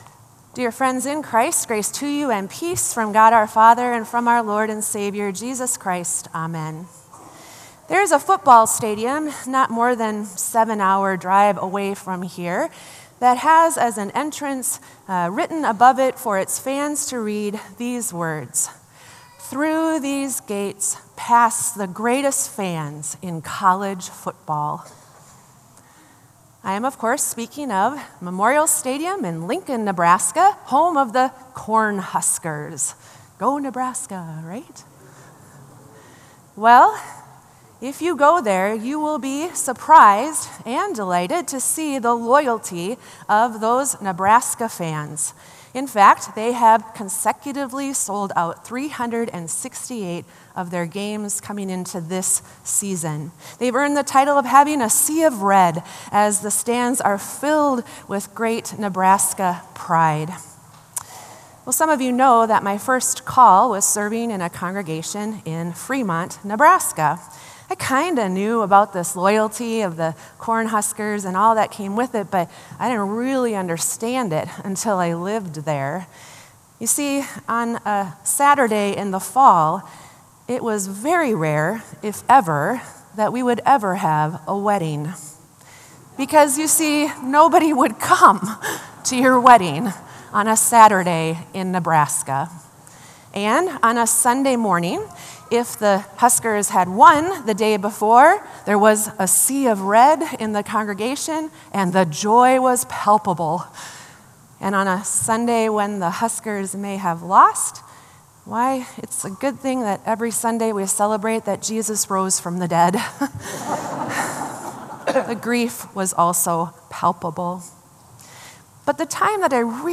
Sermon “To Be Loyal”